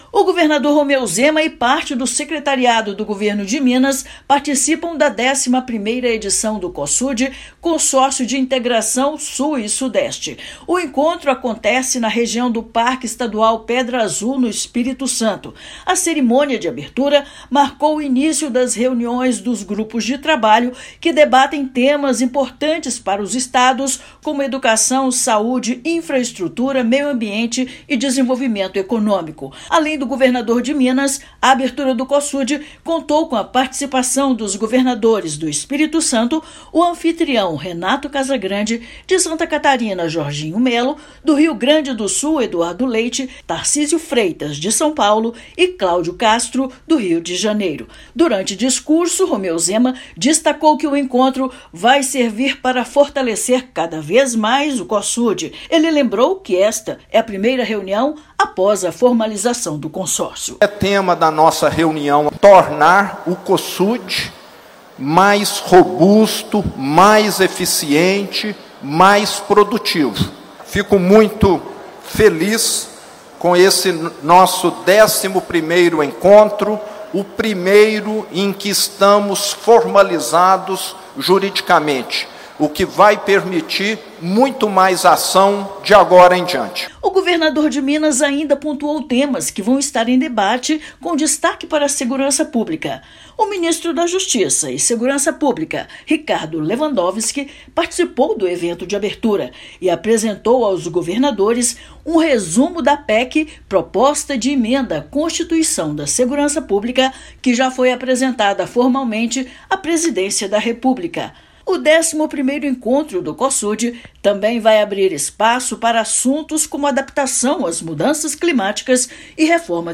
Encontro dos governadores também vai abrir espaço para discussões de temas como meio ambiente, saúde e educação. Ouça matéria de rádio.